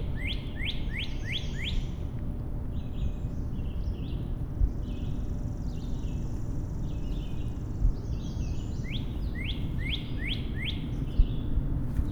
Cardinal rouge
Si tu entends un chant qui répète le son «huit», cherche d’où ça vient.